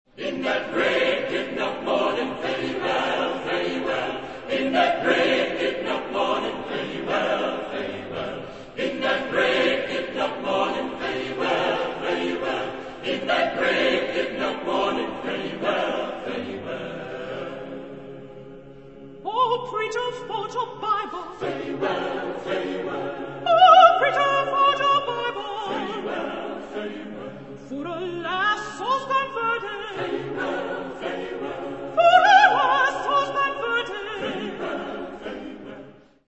Género/Estilo/Forma: Sagrado ; Gospel ; Popular ; Espiritual
Tipo de formación coral: SATB  (4 voces Coro mixto )
Solistas : Tenor (1)  (1 solista(s) )